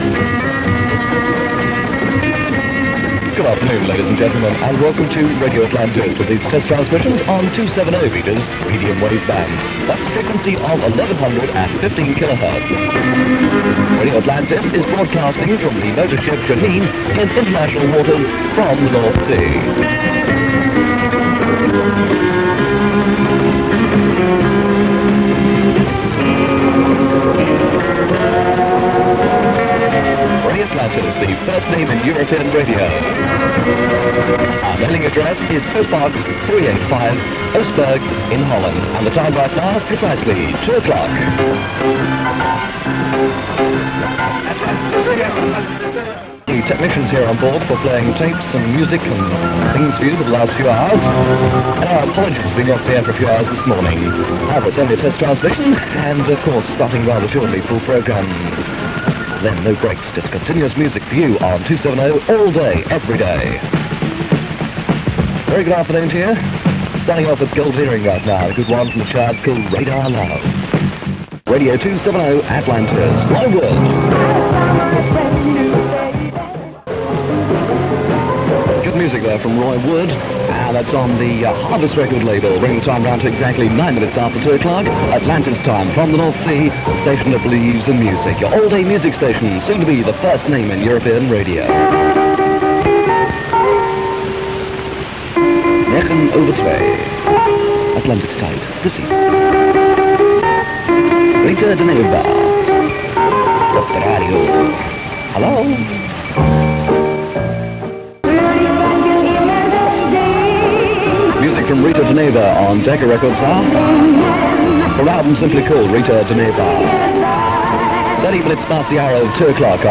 Radio Atlantis had a lower-powered transmitter than its rivals and reception was sometimes difficult.
Radio Atlantis was heavily influenced by the offshore stations of the previous decade, with plenty of jingles and an upbeat fun presentation. The music was a mix of Top 40 and oldies.
Test transmission
This is an off-air recording